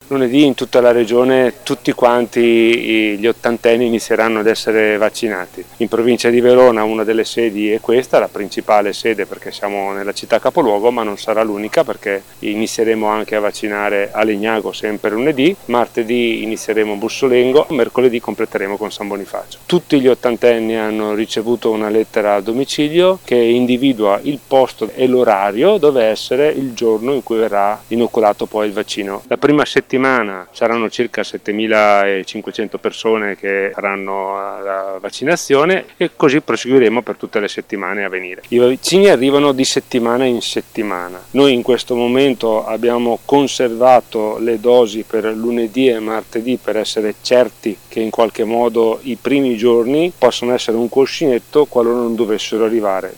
Sentiamo Pietro Girardi, Commissario dell’Ussl 9 Scaligera, che illustra il piano vaccinazione:
Commissario-dellUlss-9-Scaligera-Pietro-Girardi-sui-vaccini.mp3